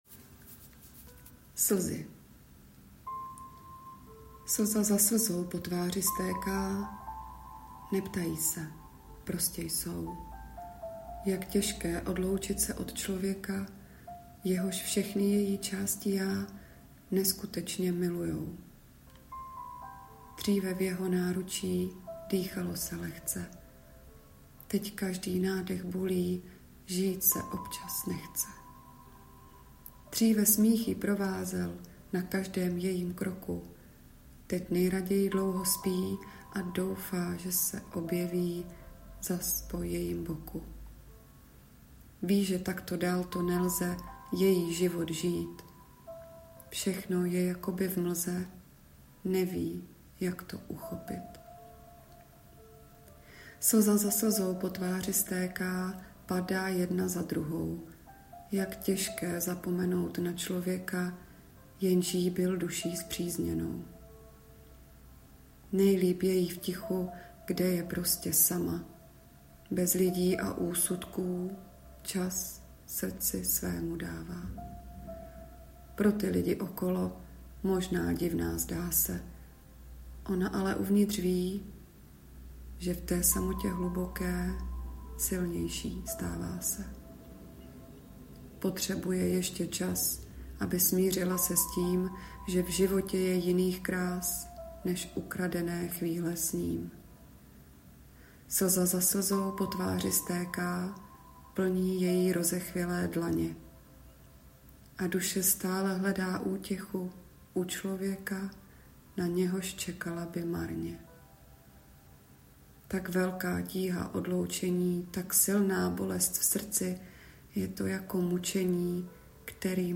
hezky namluvené, meditativní...
podbarvila jsi ji hudbou plnou klidu